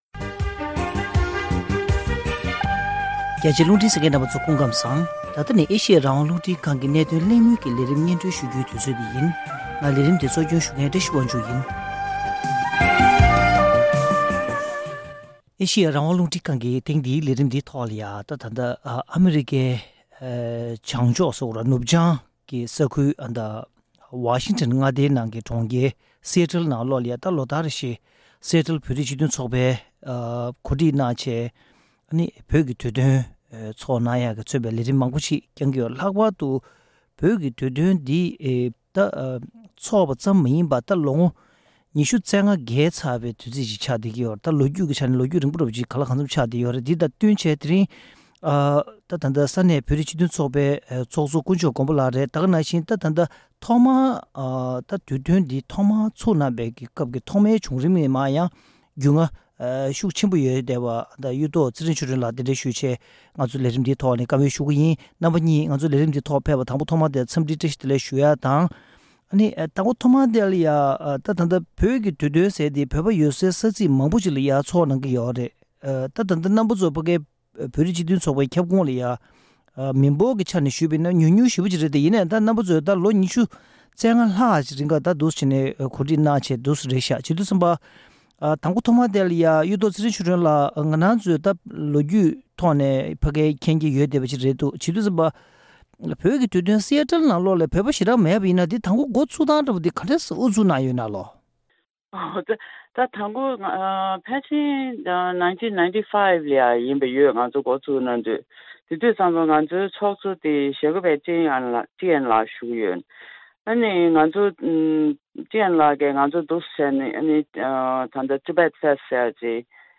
གནད་དོན་གླེང་མོལ་གྱི་ལས་རིམ་ནང་།
གླེང་མོལ་ཞུས་པའི་ལས་རིམ།